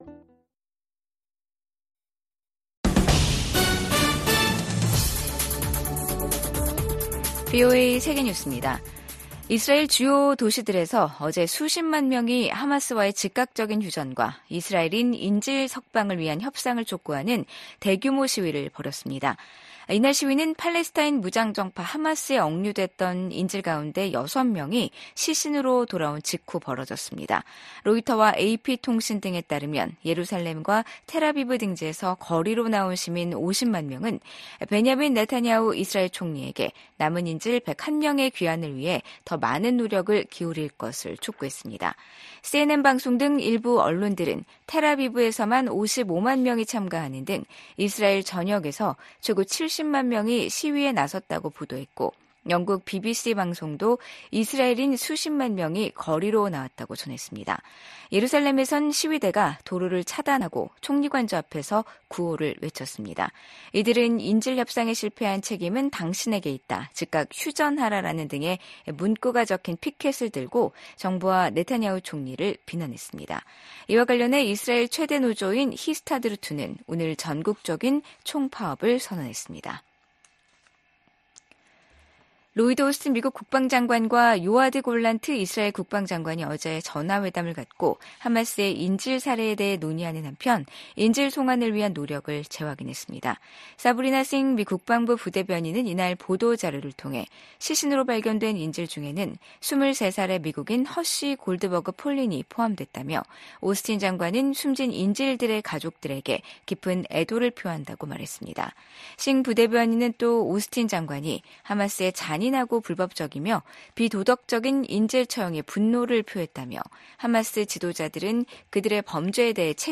VOA 한국어 간판 뉴스 프로그램 '뉴스 투데이', 2024년 9월 2일 3부 방송입니다. 대북 억지력 운용 방안 등을 논의하는 미한 고위급 확장억제전략협의체 회의가 미국 워싱턴에서 열립니다. 미국 정부의 대북정책 목표는 여전히 한반도의 완전한 비핵화라고 국무부가 확인했습니다.